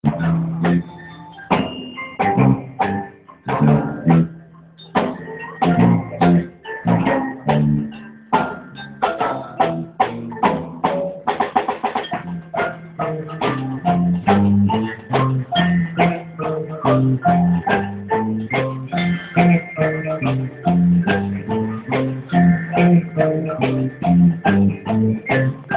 Ifjúsági koncert a Garay téren
Nem volt nálam kedvenc kis fényképezőgépem, így a Nokiával tudtam csak kép/hangrögzíteni.